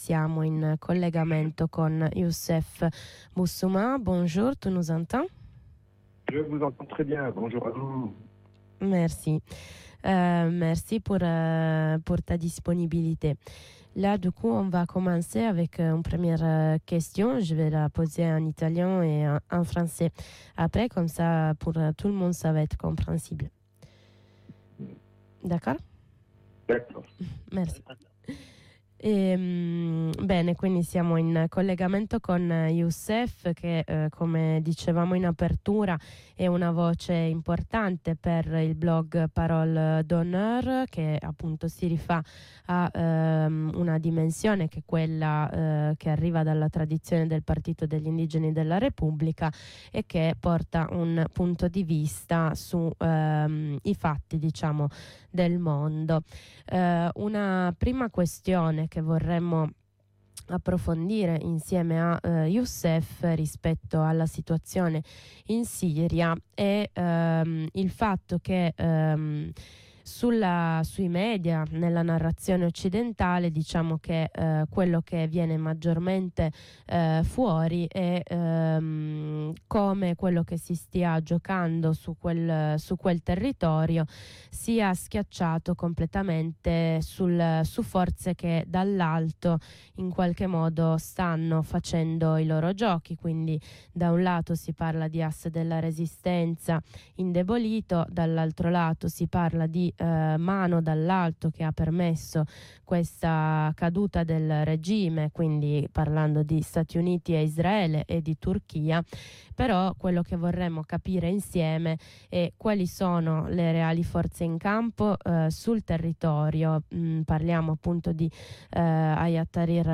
Oggi la sfida che si apre per il popolo siriano, con il quale viene ribadito più volte nel corso dell’intervista, si gioisce per la fine di una durissima dittatura, la riapertura delle prigioni e la cacciata di Assad, si riassume nella possibilità o meno di poter decidere per il proprio avvenire che risulta essere particolarmente complesso.